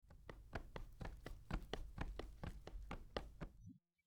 Footsteps Running
Footsteps_running.mp3